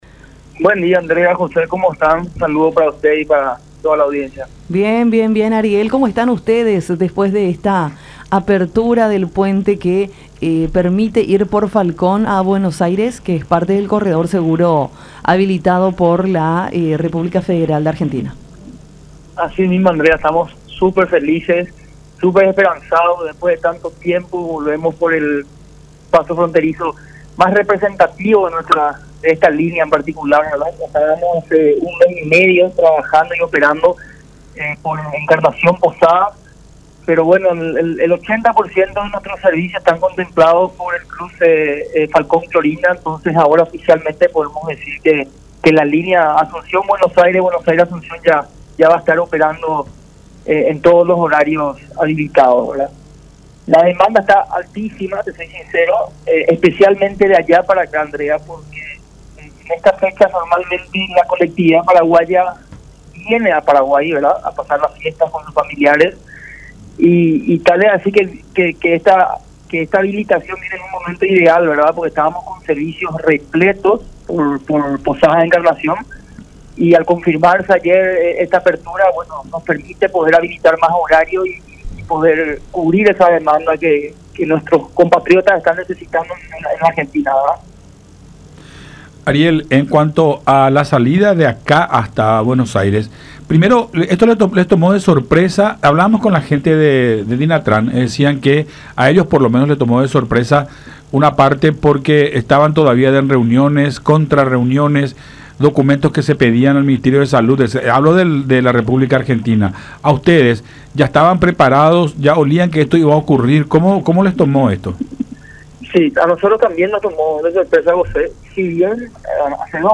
en conversación con Enfoque 800 a través de La Unión.